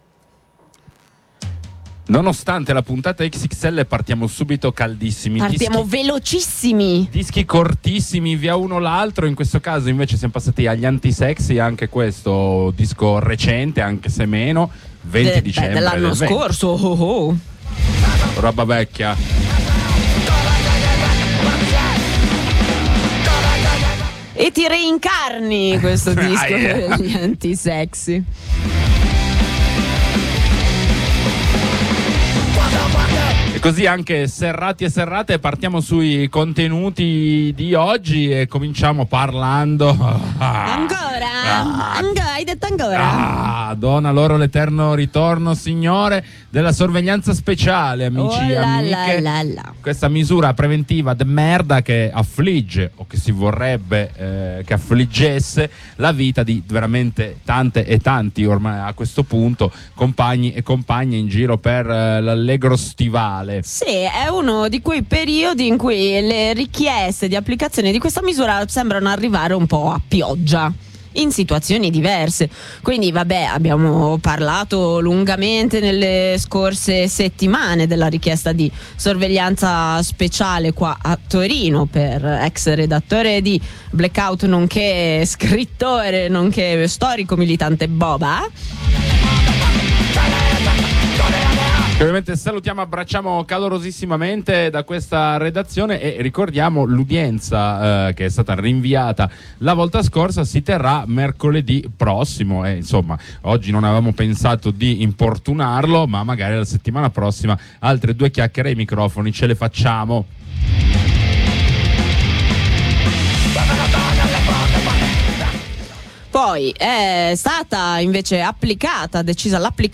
Dopo alcuni veloci aggiornamenti e la lettura di un breve comunicato da Genova abbiamo raggiunto ai microfoni un compagno (min. 8.08) e una compagna (min. 19.00), recentemente candidati dalla Procura di Bologna a Sorveglianze Speciali della durata di 5 anni.